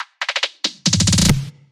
标签： 140 bpm Dubstep Loops Drum Loops 295.46 KB wav Key : Unknown
声道立体声